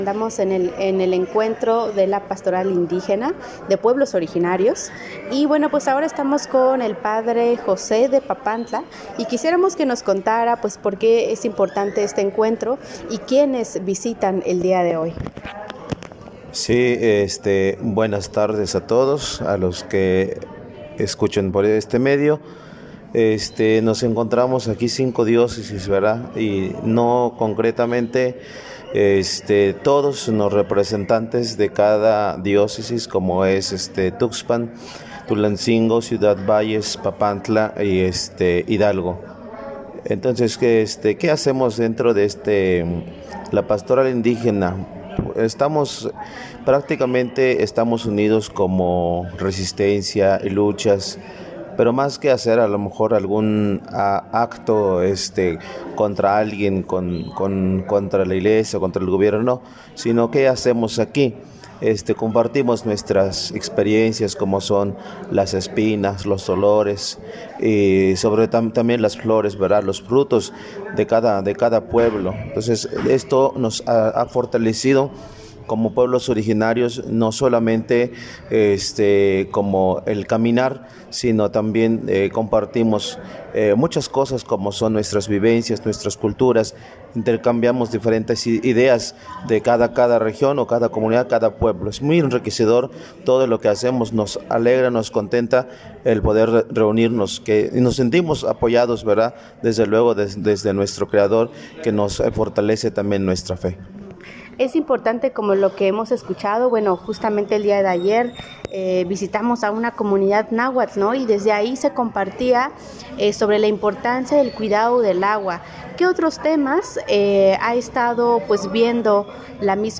Reunión de pastoral de Pueblos originarios de la Región Huasteca, se llevó acabo el 01 y 02 de Abril en Calnali, Hidalgo.